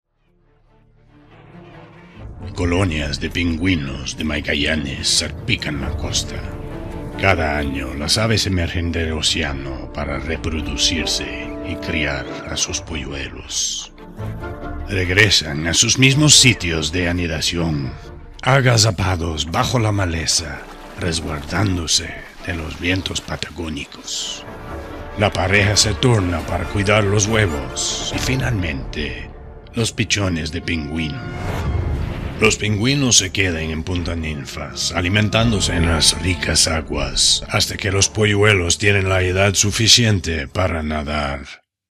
Depending on the projects needs, I will record using a Sennheiser MKH416 or a TLM 103 mic in a sound studio with Avid Pro Tools Studio Edition.